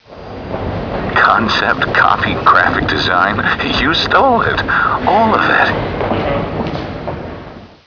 THE LONDON SUN & NEWS OF THE WORLD's confidential sources have discovered that a series of threatening and possibly revealing telephone calls are being received from a source or sources unknown who apparently have information on the whereabouts of Meg Townsend.
Written transcripts of audio clues (TXT files) are available for users without sound-cards -- but where possible, we recommend downloading the sound files, because the background sounds and audio subtleties can be helpful in formulating your theory!